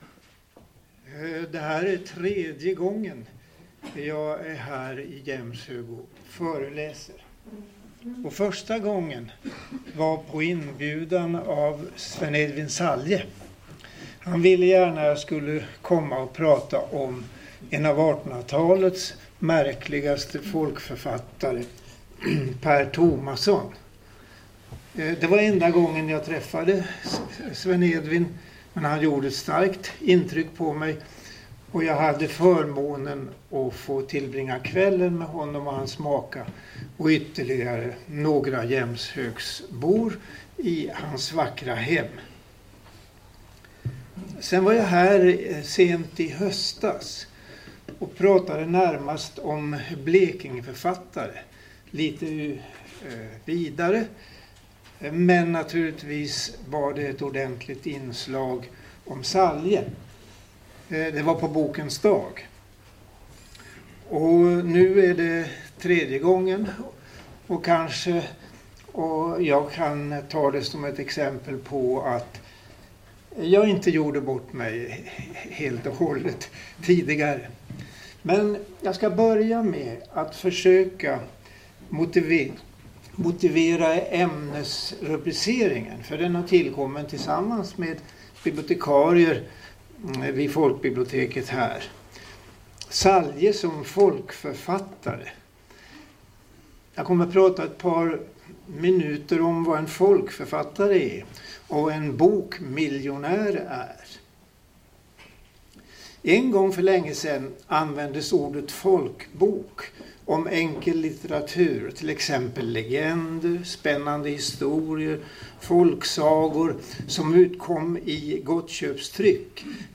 höll föredrag om Sven Edvin Salje i samband med att Saljesällskapet bildades den 14 augusti 1999.